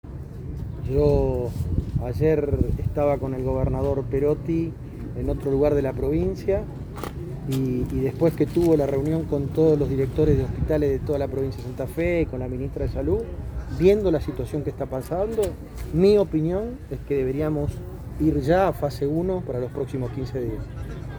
Así lo planteó el senador nacional durante su visita por el operativo de vacunación que lleva adelante el gobierno provincial en el predio de la Esquina Encendida de la Ciudad de Santa Fe.
Audio-Mirabella_vacunatorio_18-de-Mayo_parte1.mp3